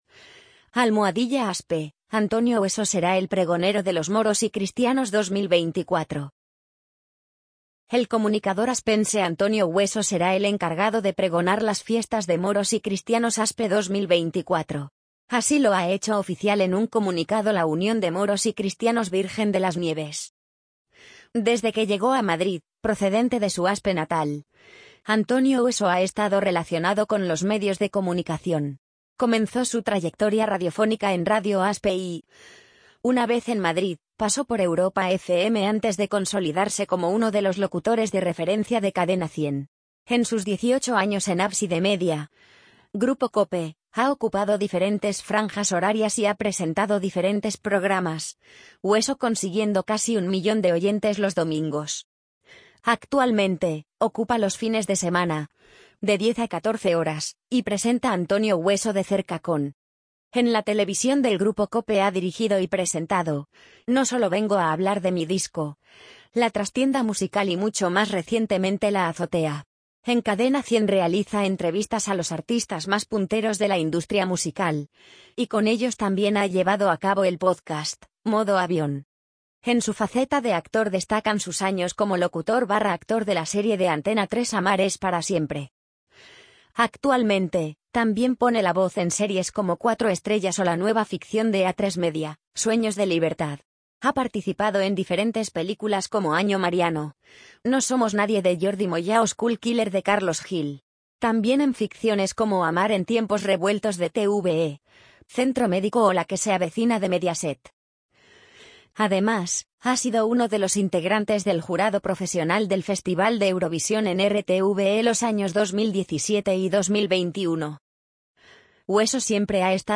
amazon_polly_71256.mp3